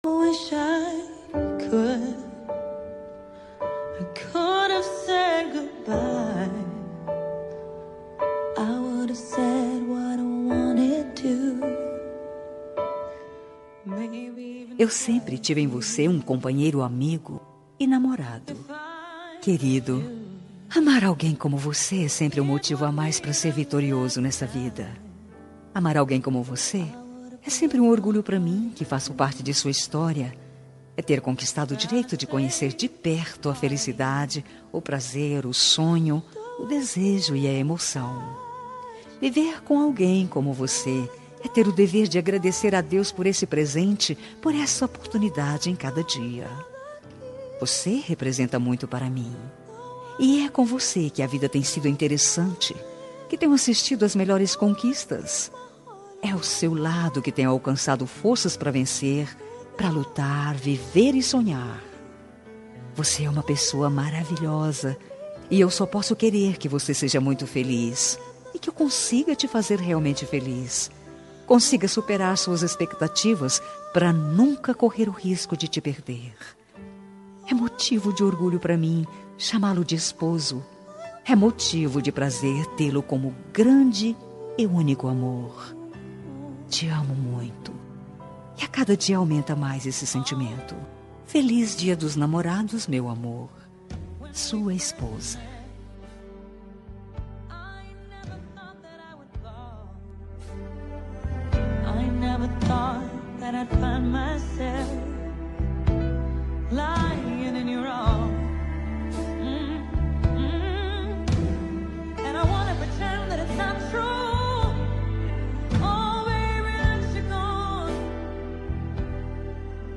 Dia dos Namorados – Para Marido – Voz Feminina – Cód: 6897